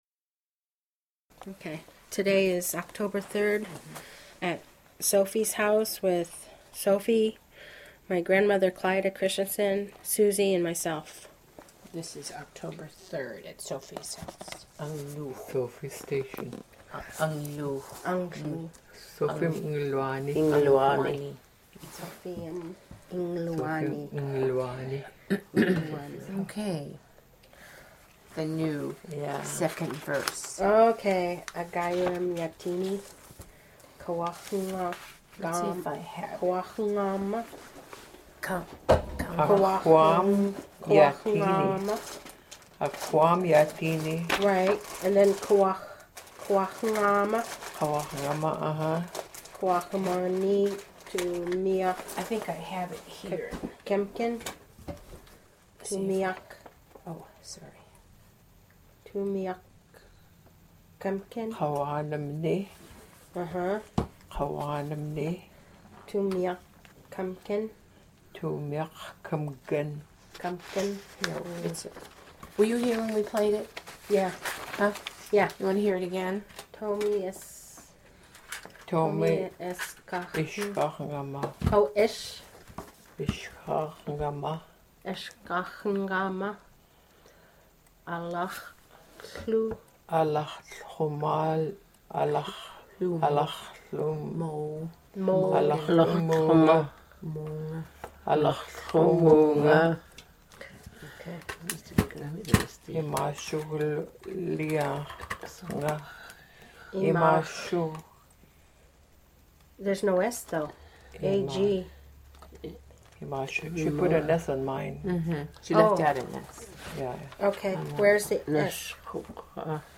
Original Format: MiniDisc (AM470:270A)